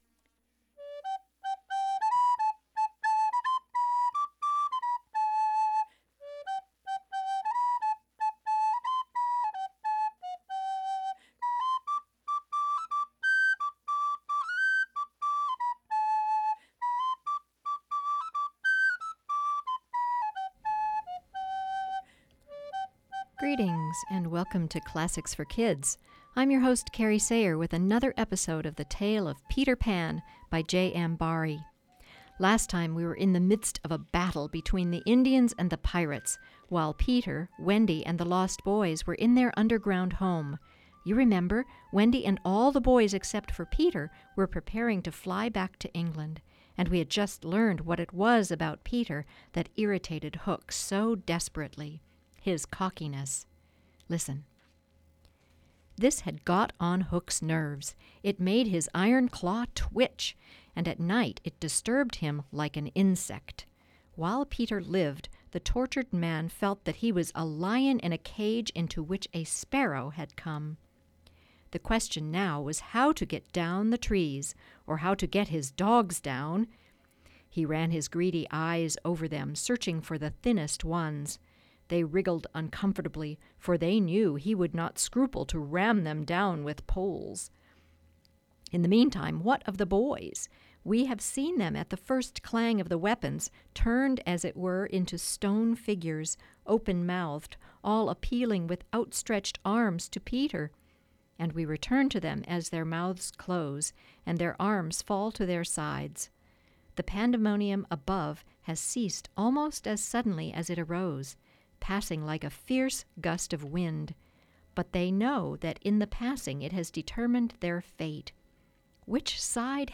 continues reading the story of Peter Pan, by J.M. Barrie.